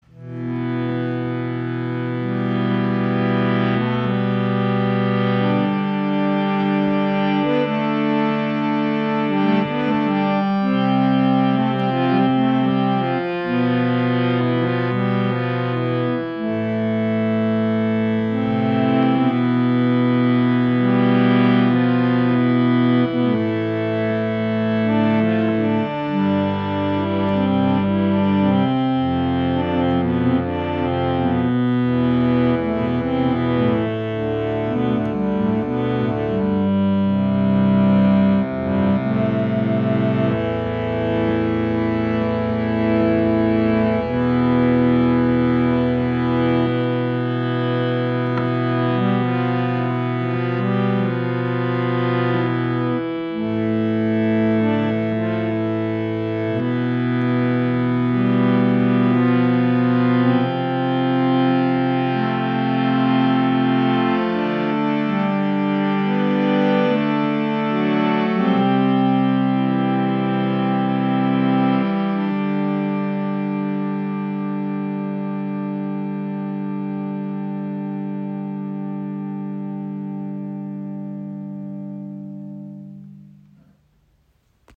Harmonium | Bhava Lite Travel | Limited Edition Mahogany
Das Bhava Travel Lite in der Limited Edition kombiniert schicke Designelemente mit einer Zedernholzkonstruktion und hat einen warmen Klang und ein unverwechselbares Aussehen.
Das Bhava Lite ist ein minimalistisches Reiseharmonium mit 32 Tasten.
Stimmung: Concert Pitch / 440 Hz